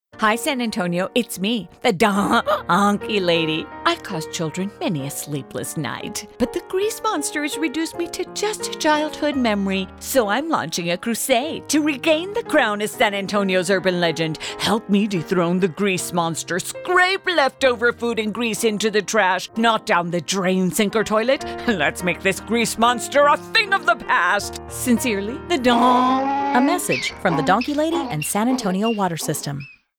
San Antonio Water System - Radio